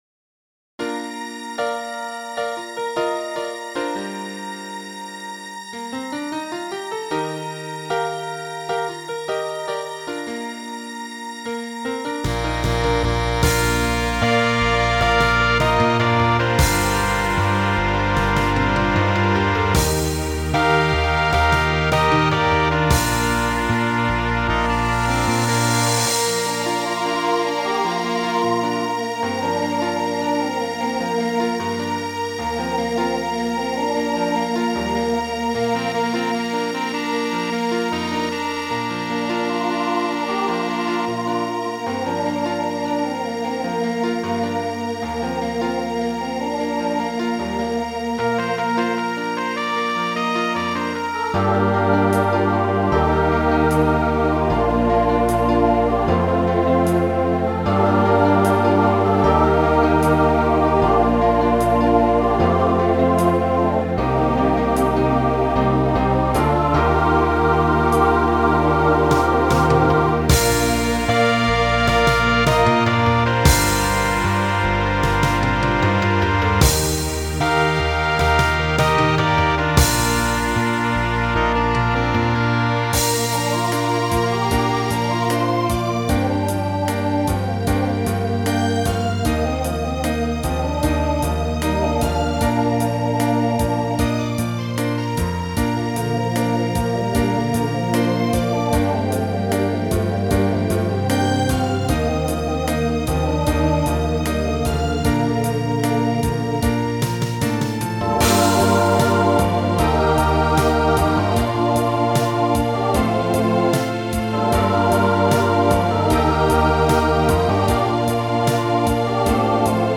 Voicing SATB Instrumental combo Genre Pop/Dance
Ballad